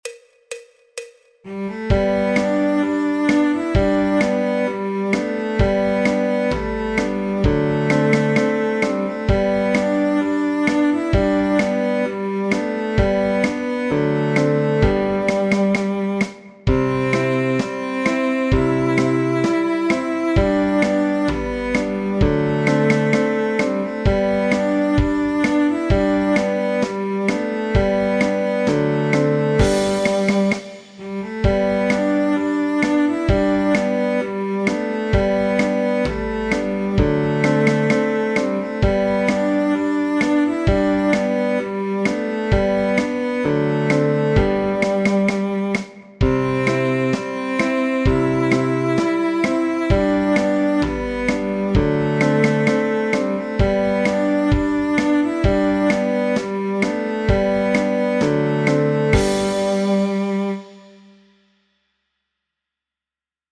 El MIDI tiene la base instrumental de acompañamiento.
Folk, Popular/Tradicional